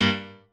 piano10_2.ogg